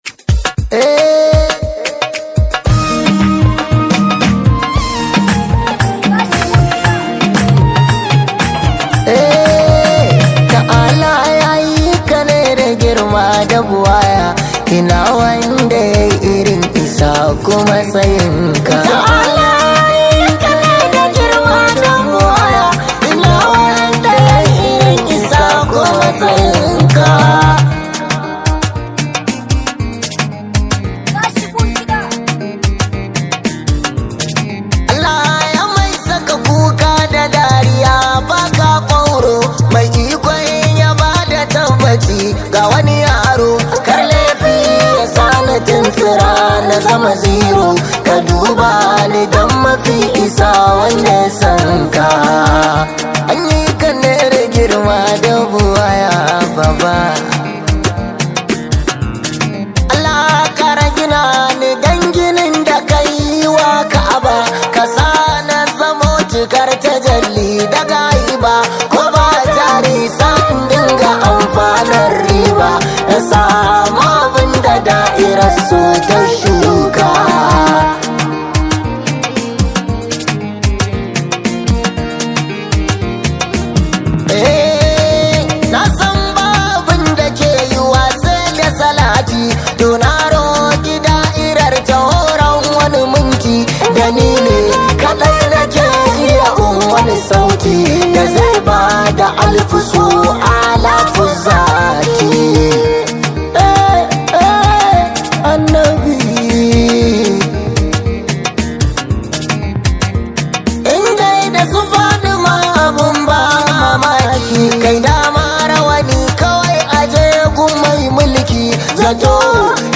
daddaɗar muryarsa
Hausa Yabo